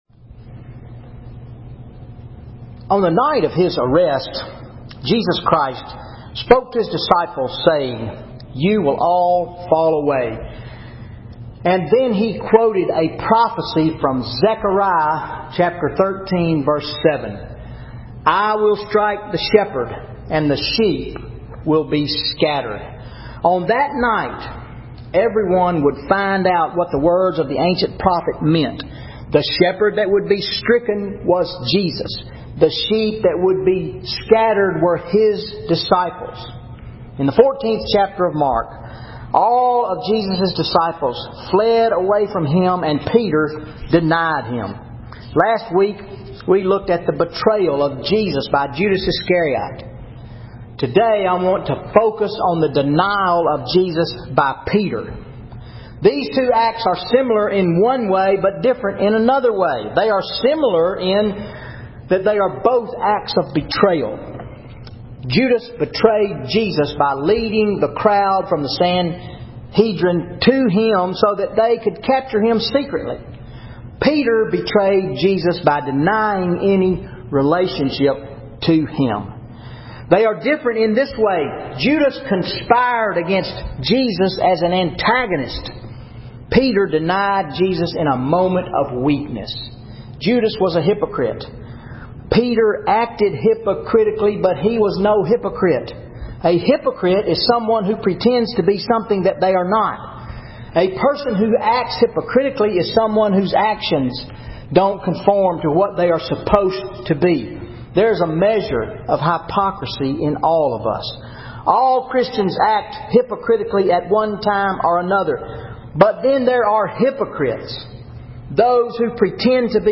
Sunday Sermon July 21, 2013 Mark 14:53-72 How Can I Deal with My Failure of My Lord?